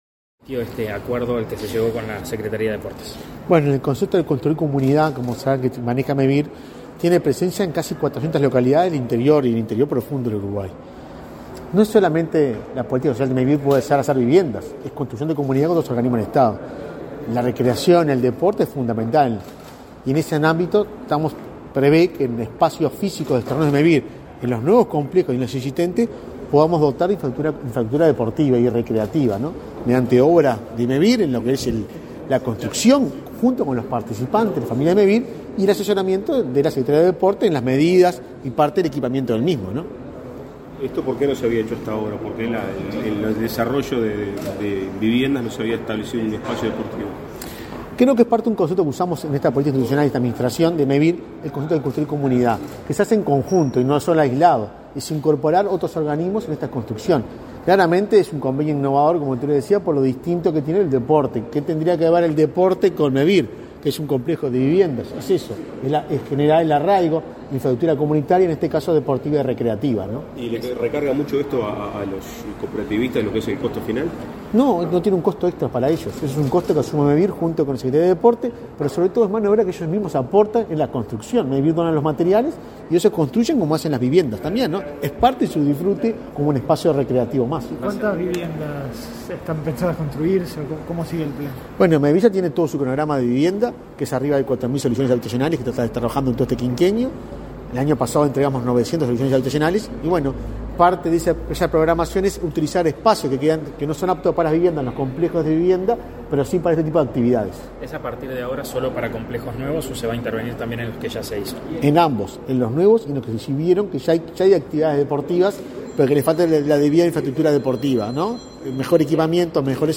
Declaraciones a la prensa del presidente de Mevir, Juan Pablo Delgado